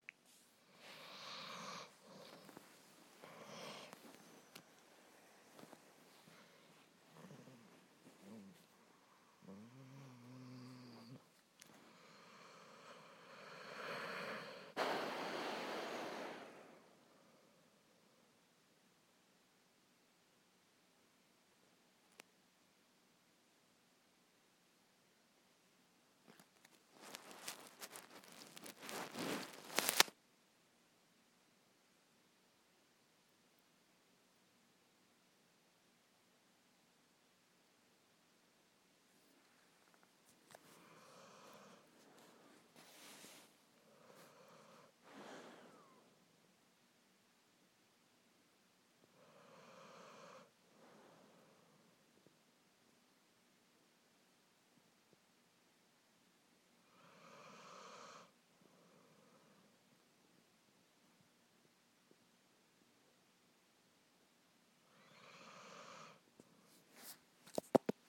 sleeping canine